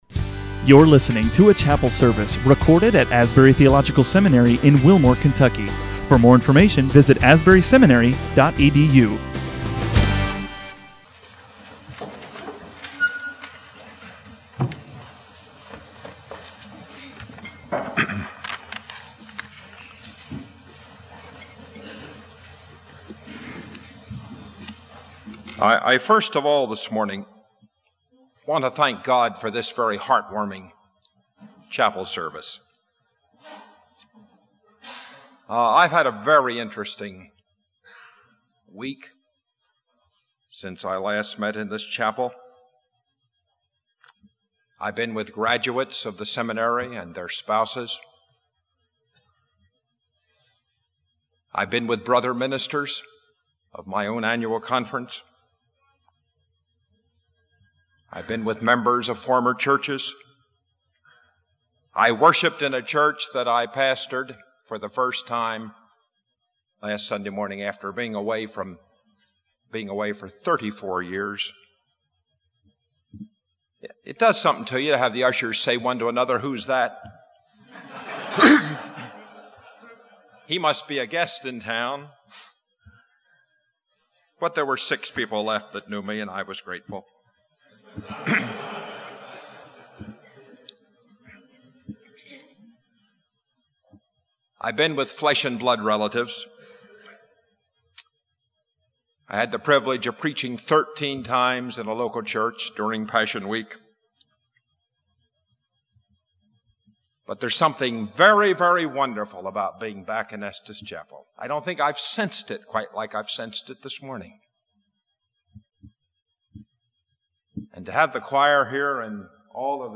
Holy Week chapel, 1982